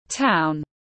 Town /taʊn/